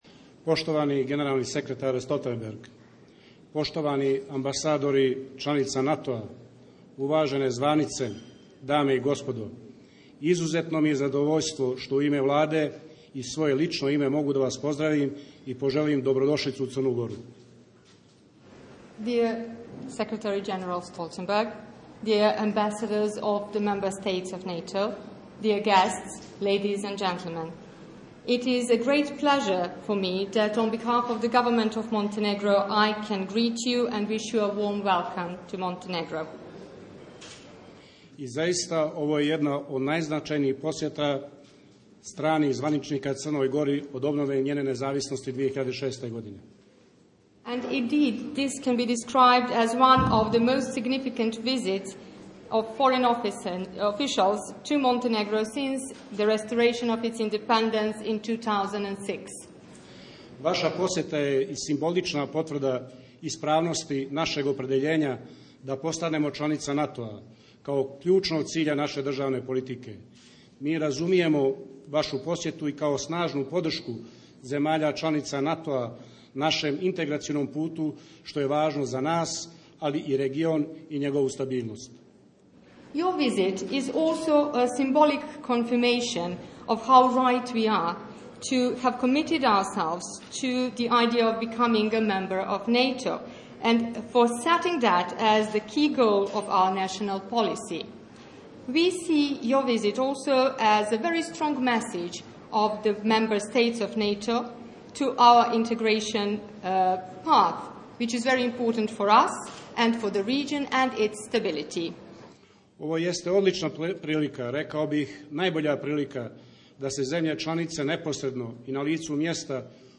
Welcoming remarks by the Deputy Prime Minister of Montengro, Dusko Markovic and NATO Secretary General Jens Stoltenberg